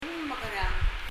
発音
mekeráng　　[mɛkɛraŋ]　　　　do what?